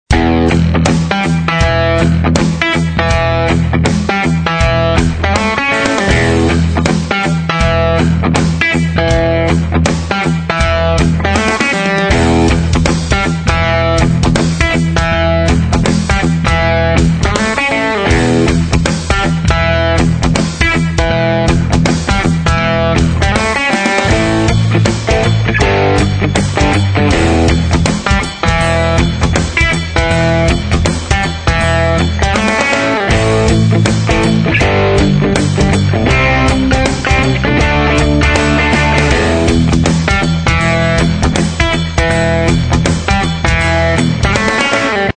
描述：慢摇 / 蓝调轨道，在一个类似的风格 ZZ 顶，沟鼓和模糊吉他
摇滚音乐